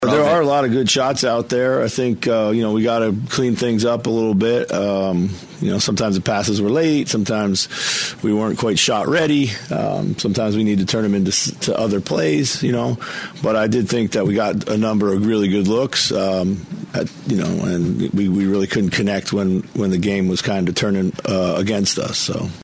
Wolves Head Coach Chris Finch admits says they had good looks at the basket but couldn’t finish.